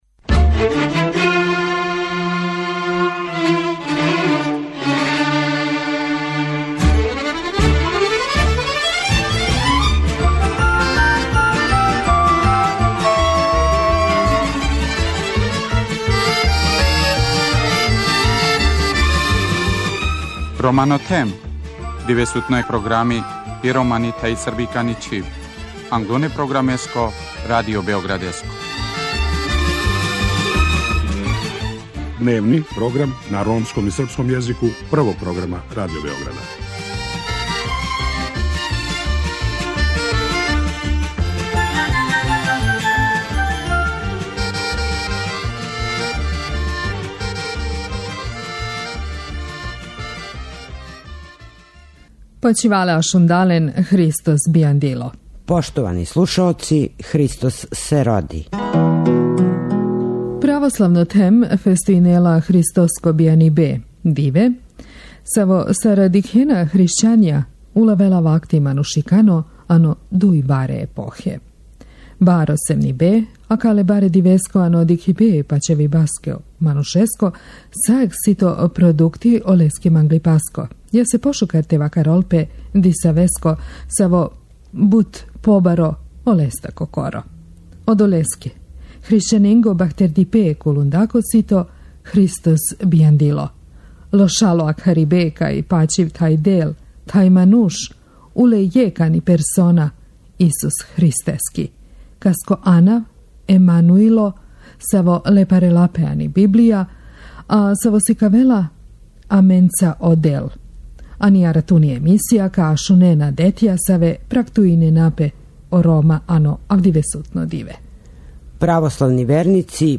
Након успешних наступа у у Паризу, Загребу, Лондону, Монтреалу и Београду мјузикл који изводи ова група, на веома креативан начин, комбинујући хип-хоп са традиционалним звуцима, дочарава слике из живота припадника ромске заједнице.